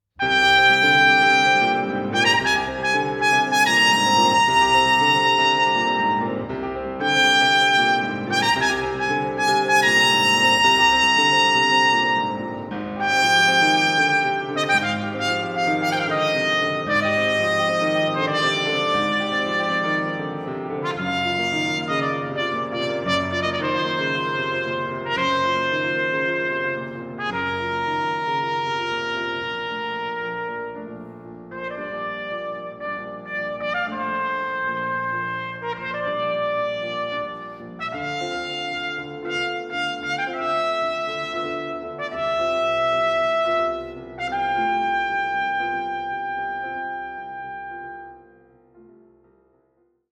Instrument: trumpet and piano with MP3
For solo trumpet with piano and performance MP3 download.